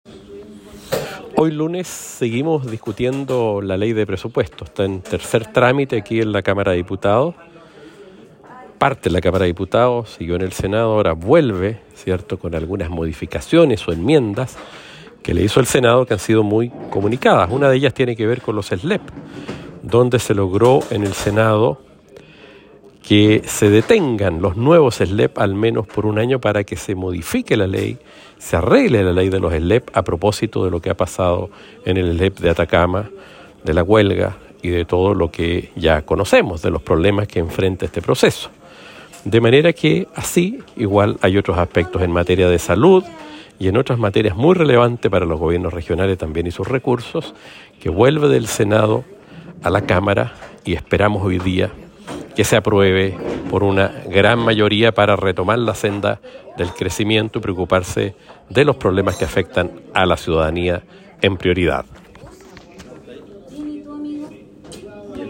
Audio Diputado Jaime Mulet, quien se refiere a la suspensión transitoria de los SLEP